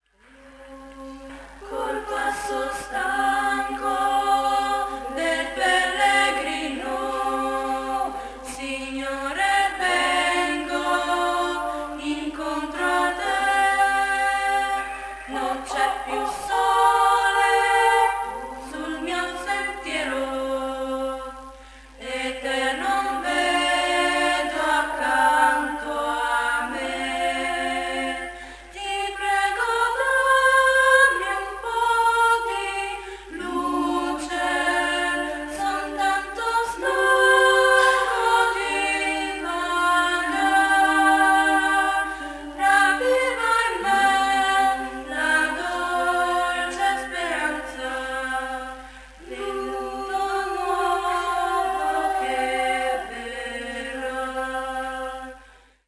Coretto parrocchiale "Les Mariutines" di Tomba